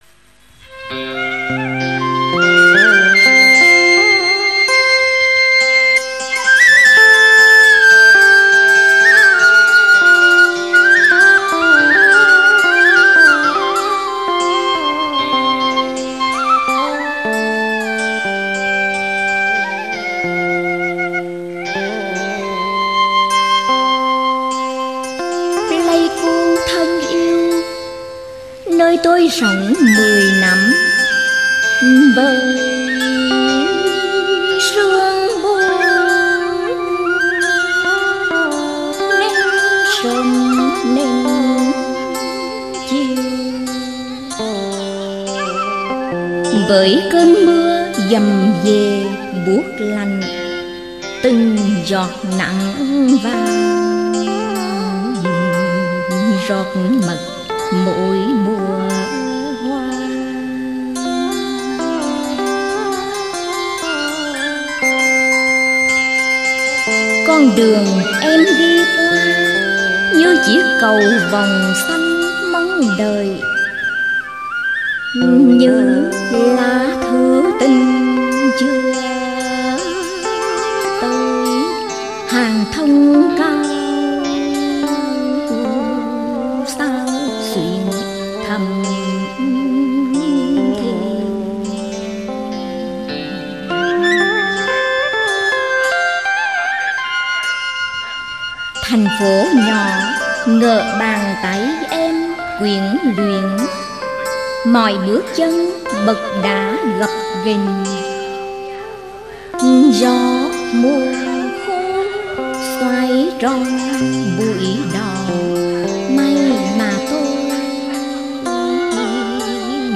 Ngâm